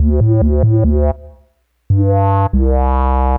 bass02.wav